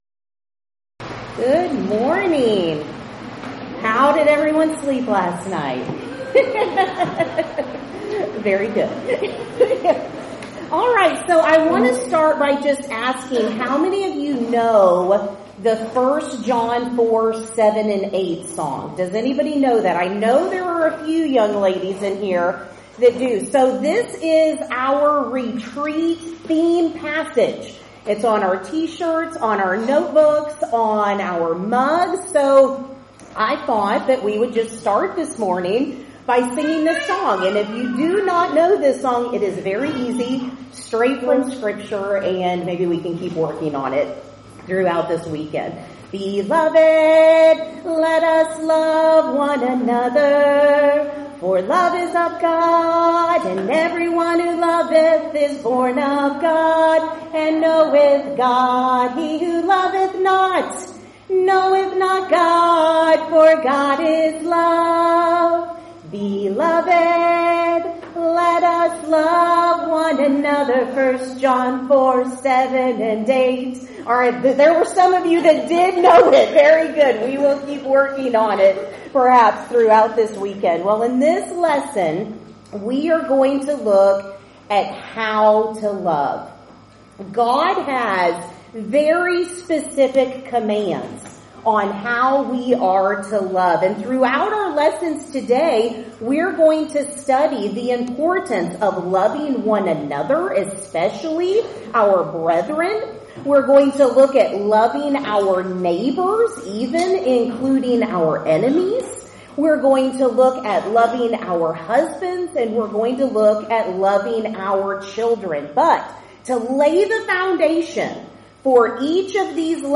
Event: 8th Annual Women of Valor Ladies Retreat
Ladies Sessions